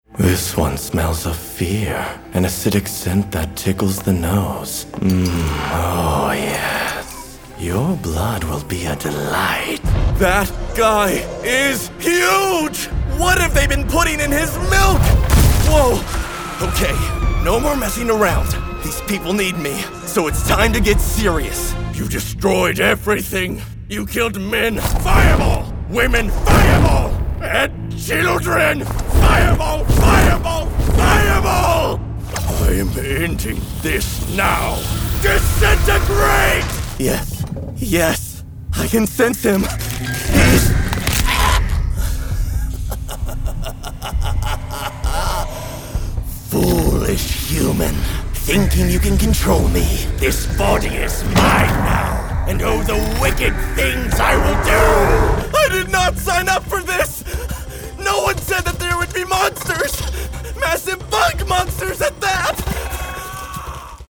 Youthful, Gen Z, energetic male voice with a natural, conversational tone.
Character / Cartoon
Wide Range Of Character Reads
Words that describe my voice are Conversational, Young, Energetic.